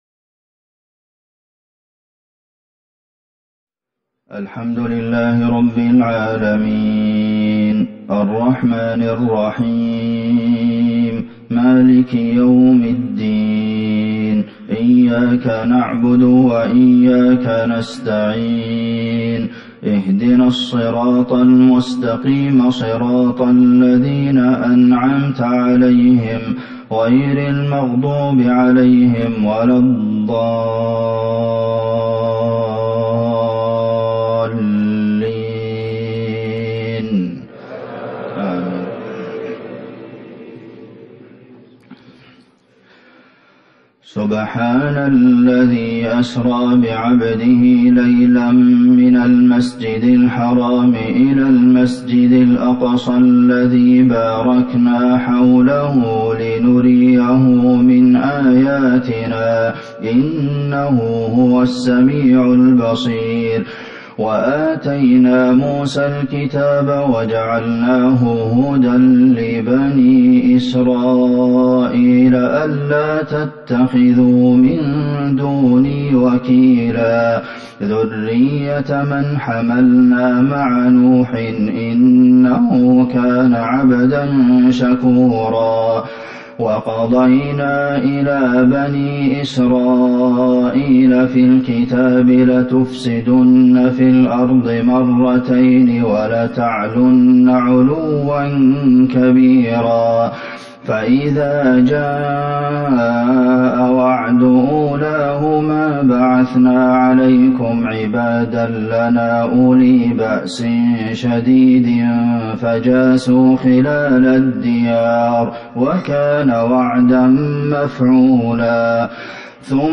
صلاة الفجر ١٣ جمادي الاخره ١٤٤١هـ سورة الاسراء Fajr prayer 7-2-2020 from Surat Al-Isra > 1441 🕌 > الفروض - تلاوات الحرمين